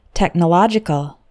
Fast: